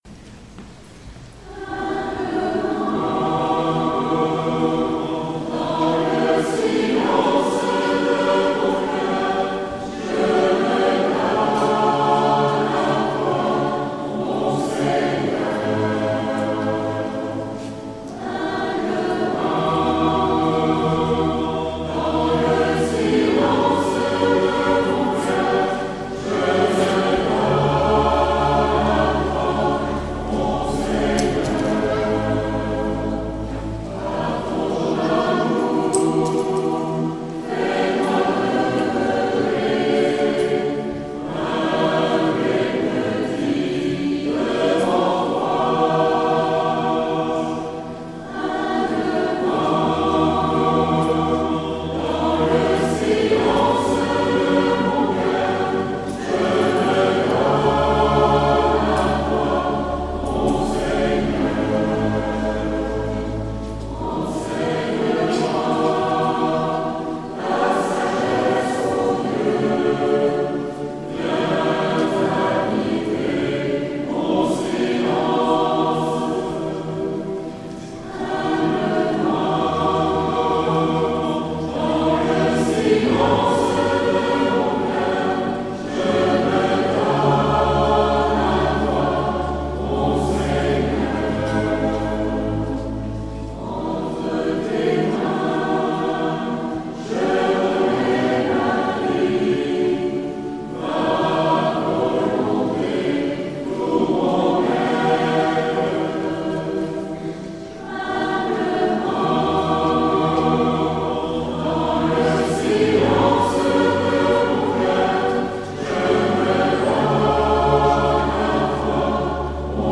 Tutti
Humblement-dans-le-silence-Tutti.mp3